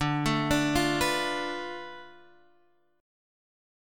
D Minor 6th